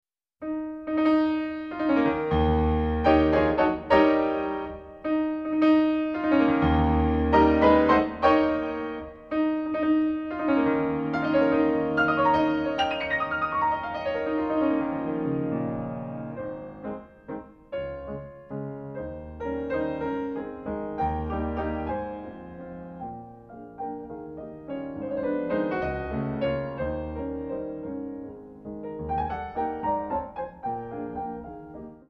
Pianist
refined, patrician touch
A flat major